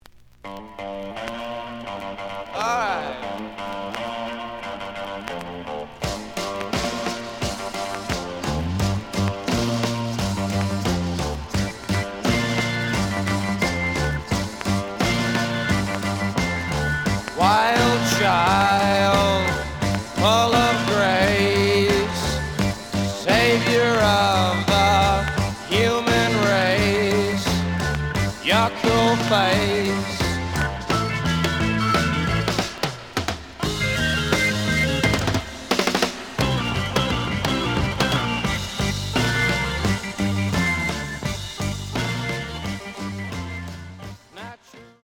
The audio sample is recorded from the actual item.
●Genre: Rock / Pop
Some click noise on beginning of B side, but almost good.)